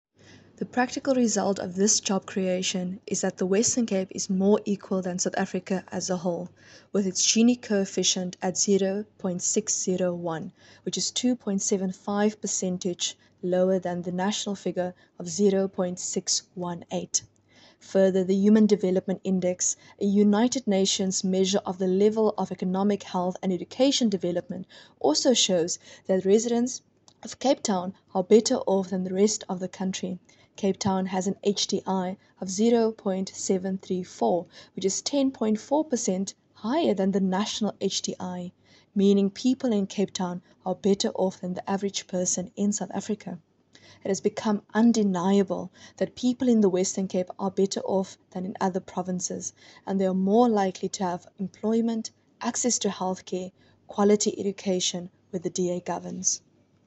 English soundbite from MPP Cayla Murray attached.